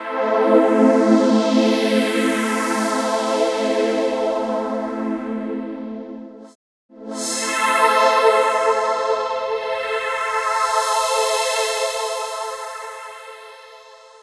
Session 04 - Trance Pad 01.wav